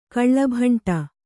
♪ kaḷḷabhaṇṭa